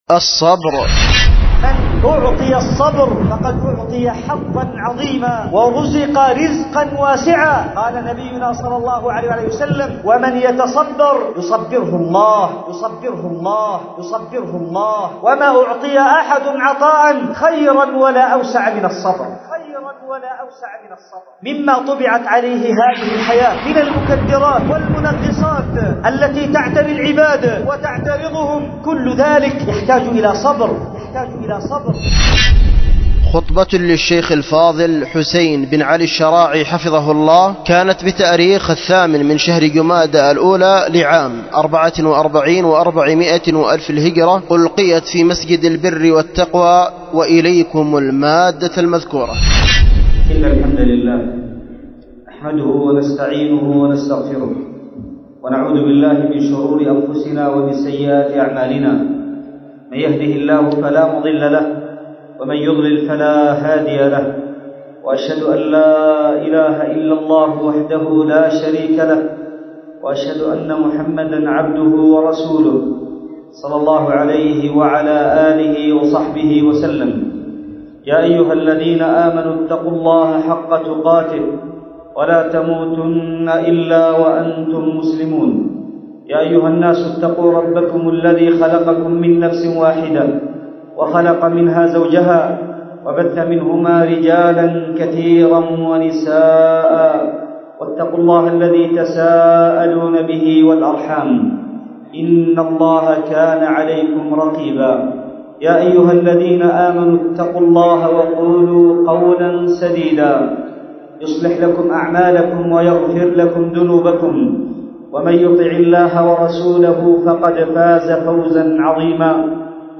ألقيت في مسجد البر والتقوى